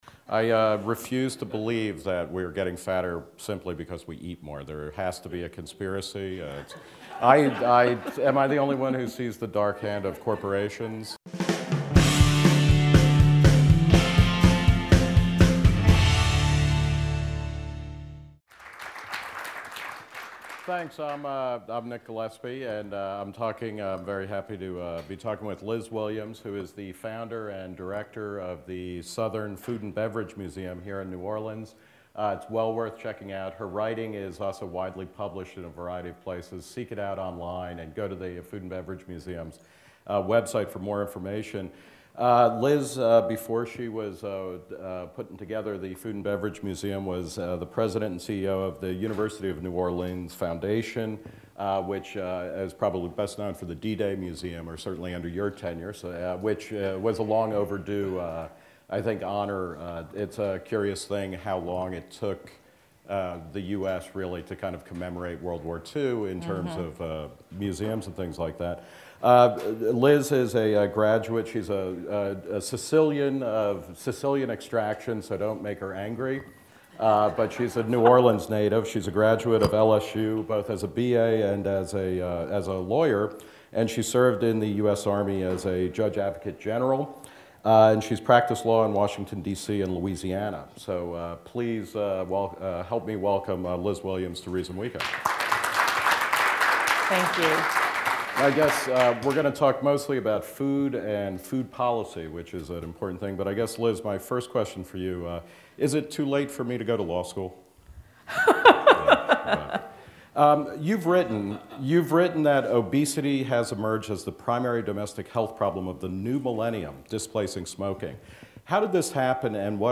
Reason Weekend 2010